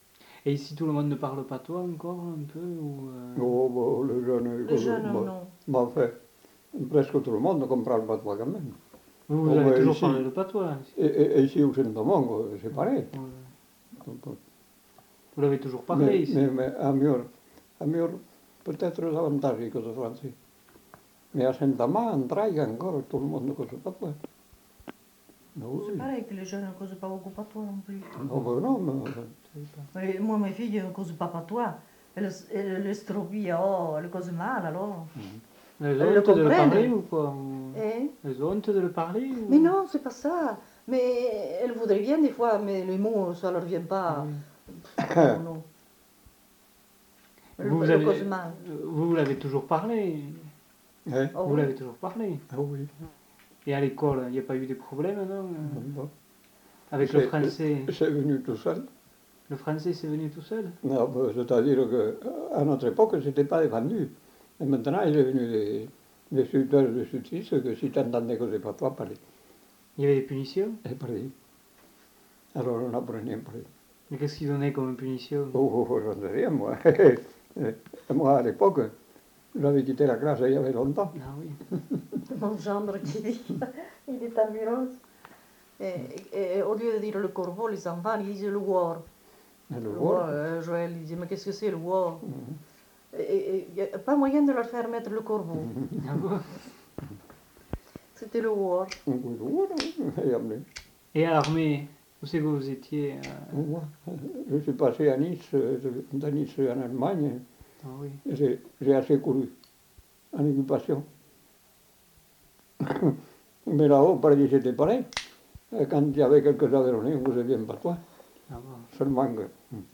Aire culturelle : Viadène
Lieu : Lacroix-Barrez
Genre : témoignage thématique